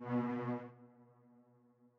Buildup_1.wav